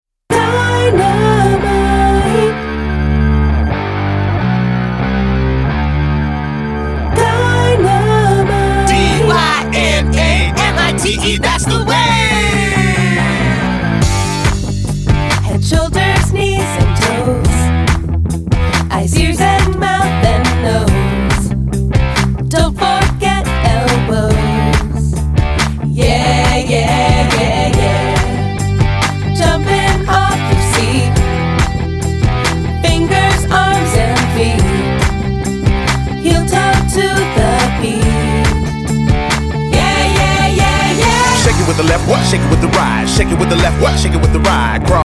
movement song!